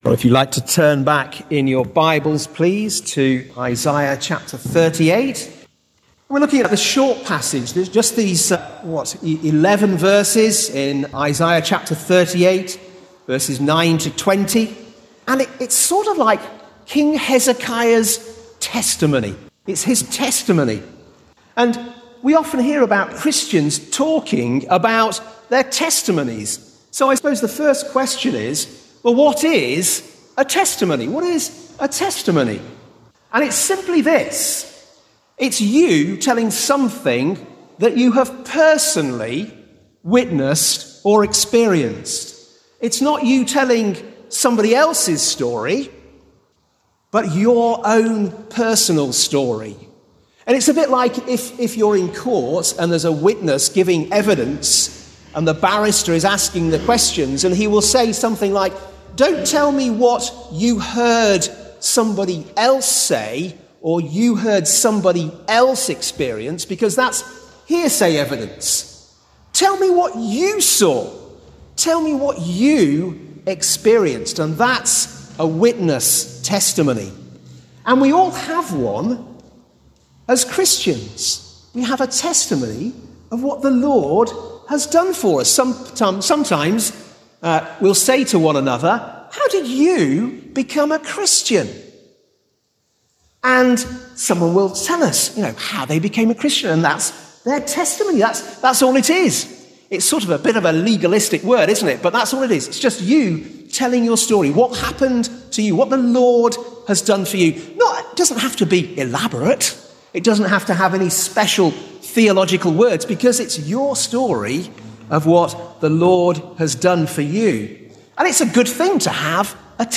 'Isaiah: The Fifth Gospel' Sermon Series: Ashbourne Baptist Church 2025